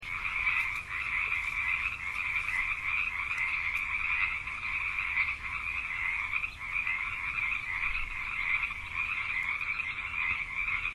Frogs.ogg